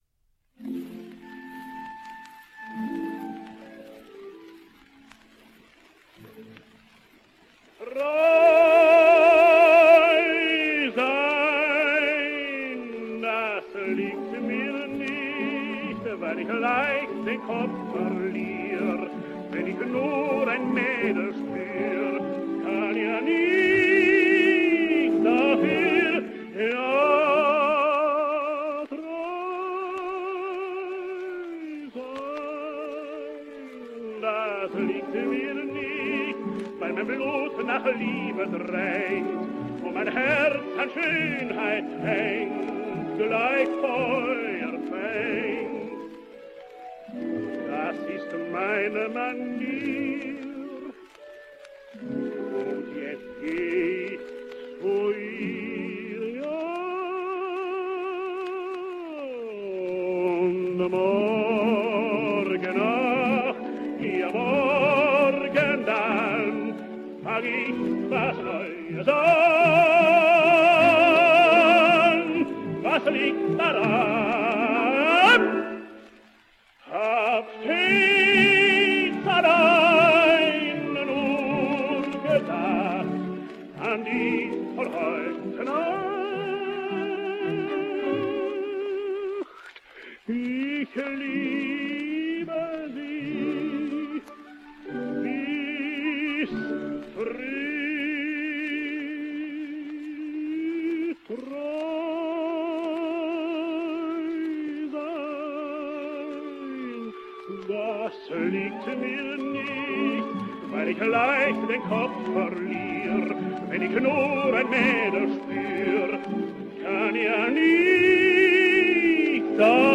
for our operetta extract